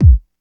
• Rich Lows Kick Single Shot B Key 515.wav
Royality free steel kick drum sample tuned to the B note. Loudest frequency: 91Hz
rich-lows-kick-single-shot-b-key-515-9IE.wav